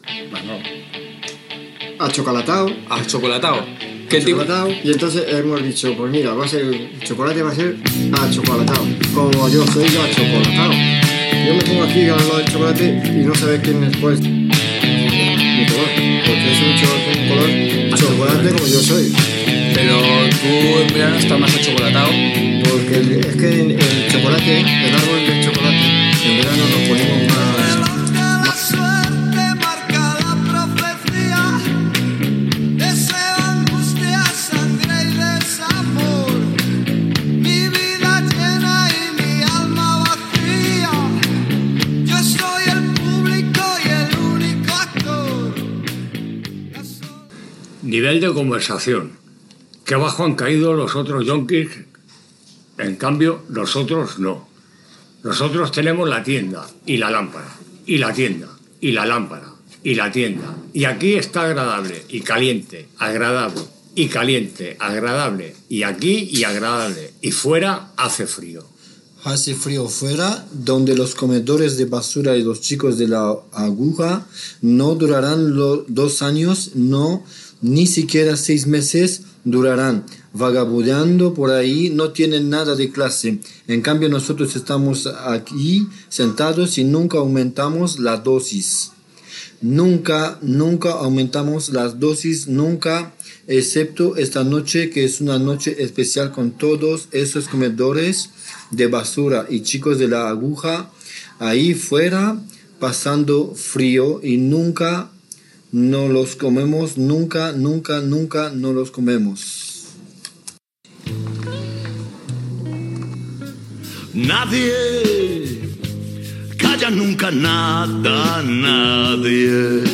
Lectura d'un fragment de “El almuerzo desnudo”, de William S. Burroughs i reflexions sobre l'infern de la droga.
Divulgació
El programa es produia a l'estudi de ràdio de la cooperativa Colectic del Raval de Barcelona.